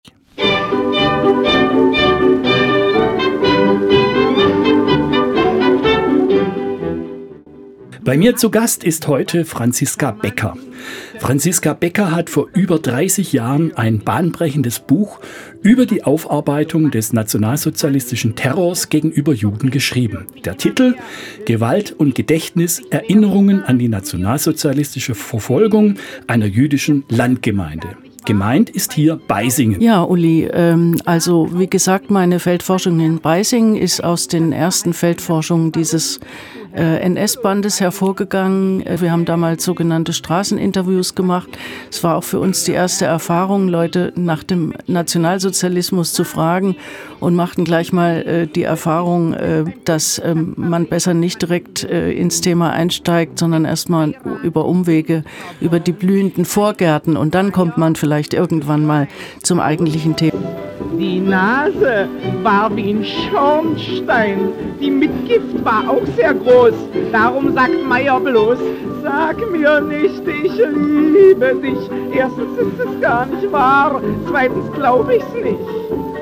Im Studiogespräch